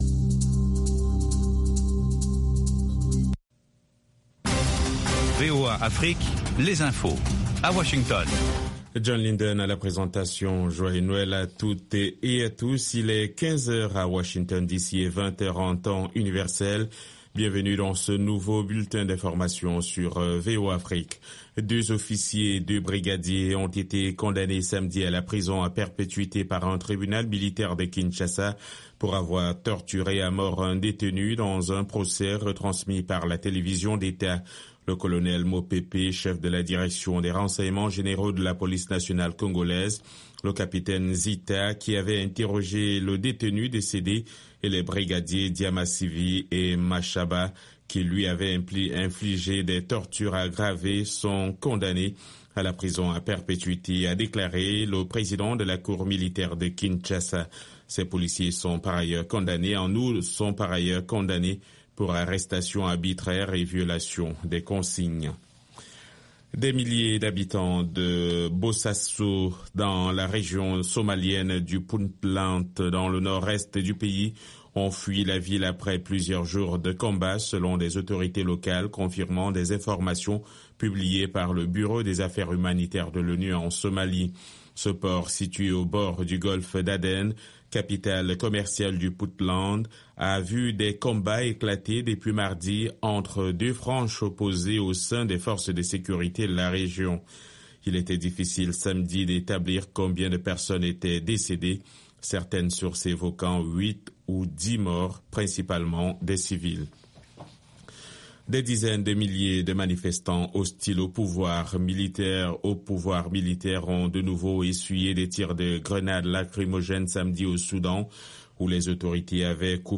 - Les orchestres gigantesques et les groups qui ont grandement contribué a changer les mœurs et société, ce classement comprend divers genre musicaux (Rap, Rock, Pop, R&b etc.) afin de satisfaire le plus grand nombre.